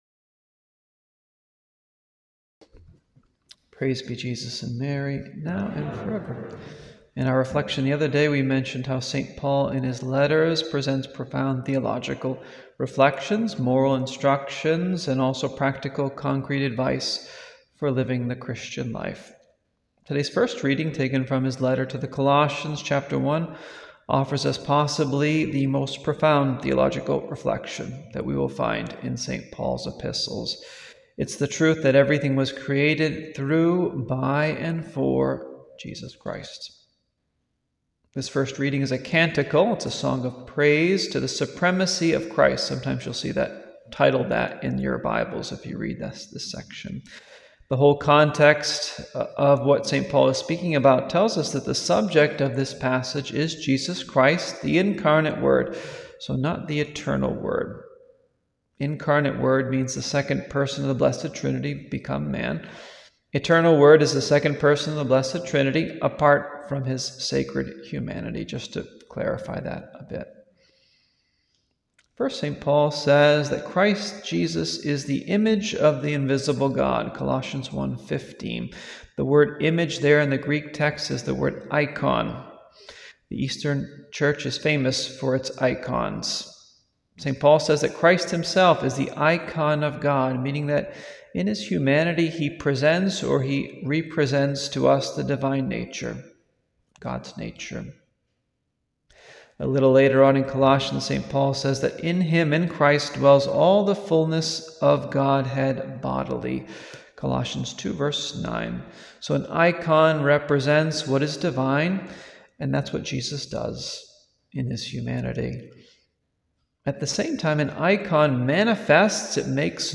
Homily